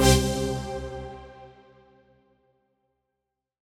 FR_ZString[hit]-C.wav